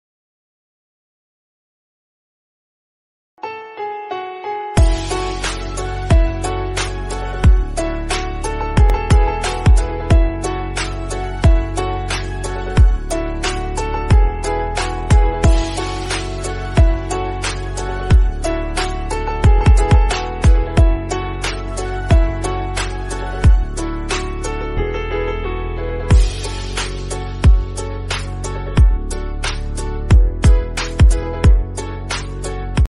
Categories Devotional Ringtones